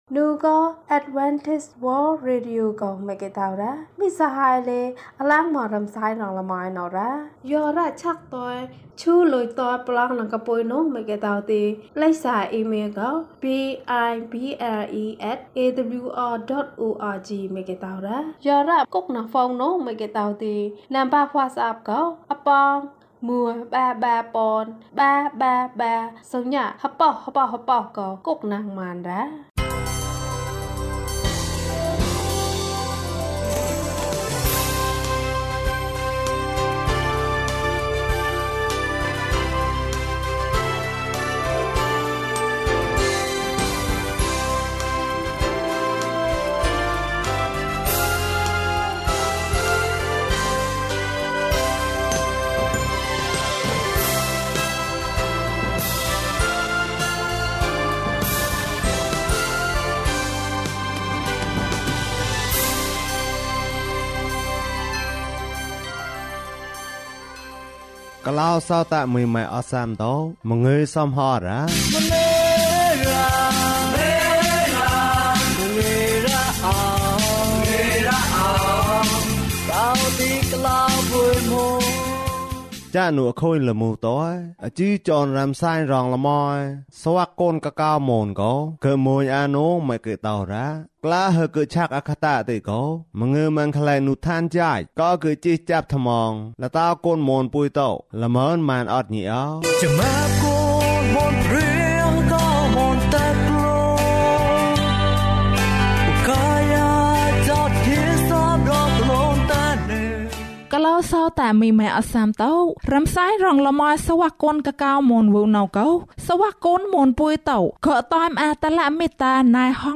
ပျော်ရွှင်သော မိသားစုဘ၀၊ ၀၂၊ ကျန်းမာခြင်းအကြောင်းအရာ။ ဓမ္မသီချင်း။ တရားဒေသနာ။